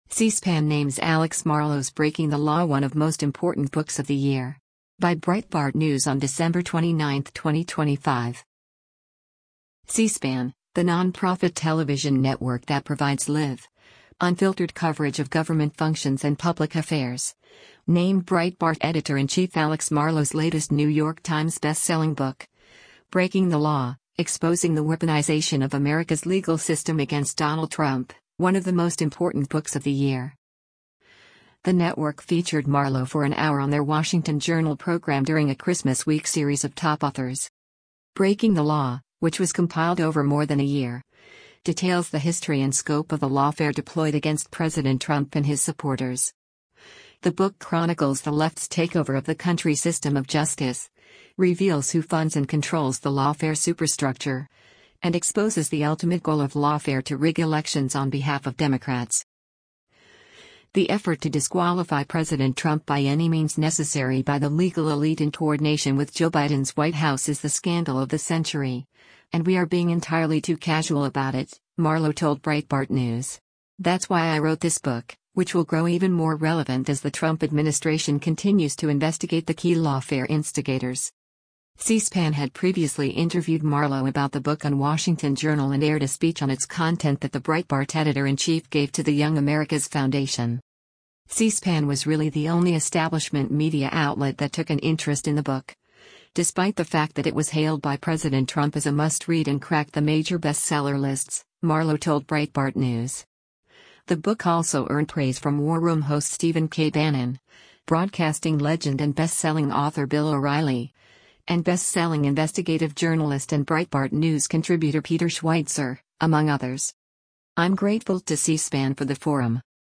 All of this takes place with no commercial breaks over a full hour.